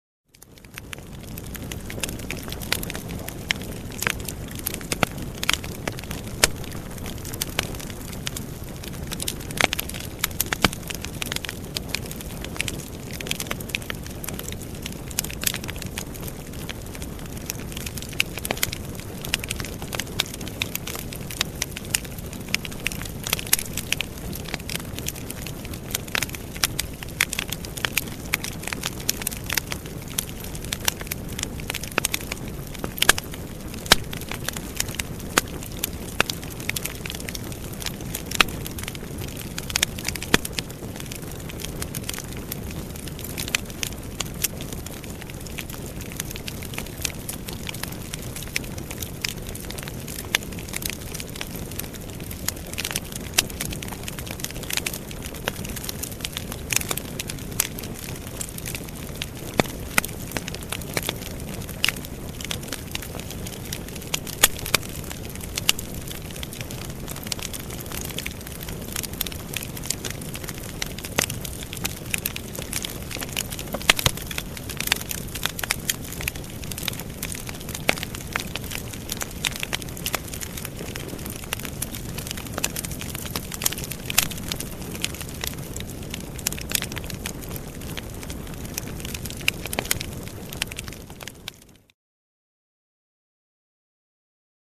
Костёр, характерные щелчки и потрескивание
• Категория: Треск костра
• Качество: Высокое